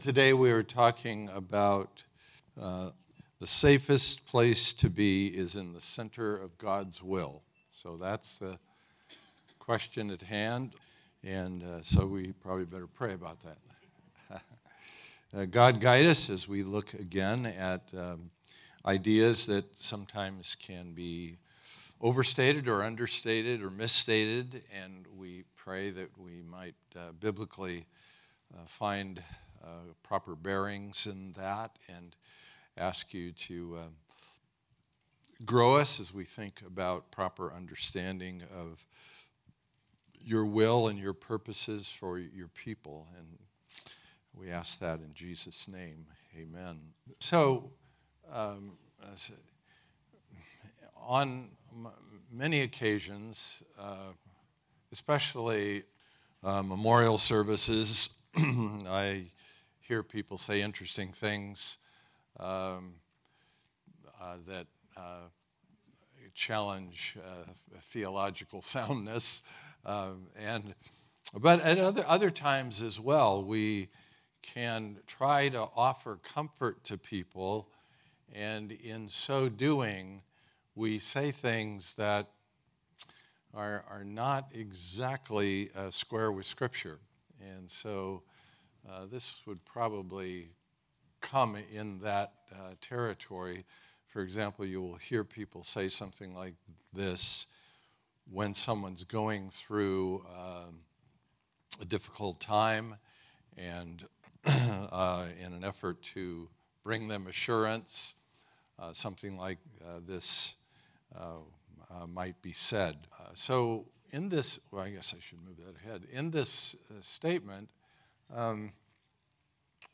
Listen to Message
Type: Sunday School